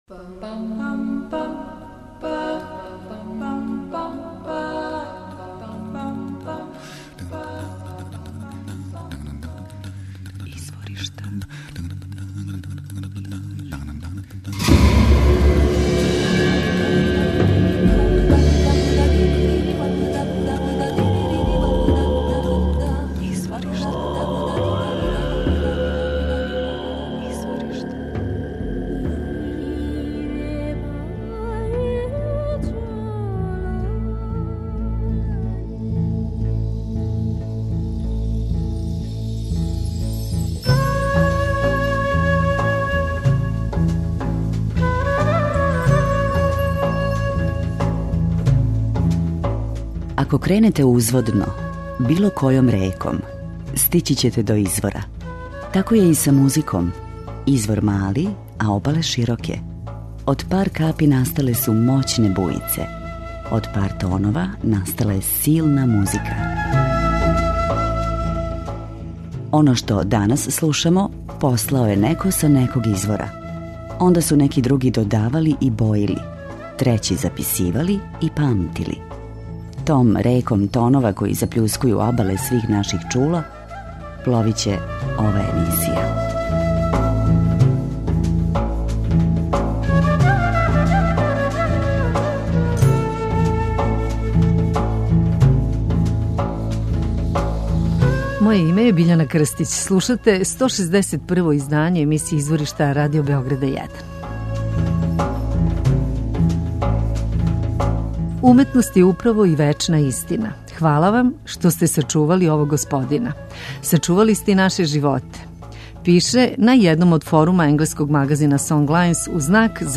Wolof и афро - кубанског звука
саксофониста
гитариста